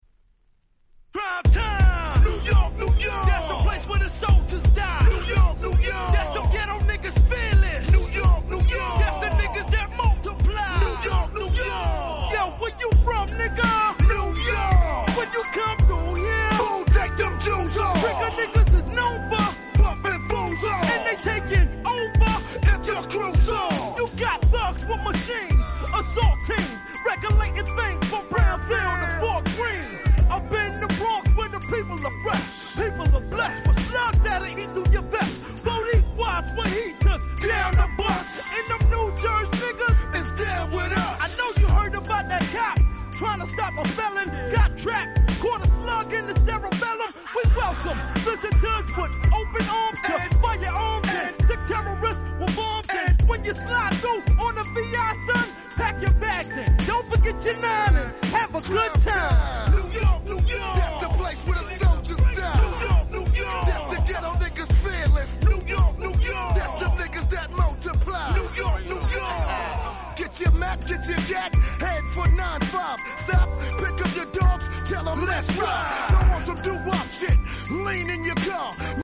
98' Smash Hit Hip Hop LP !!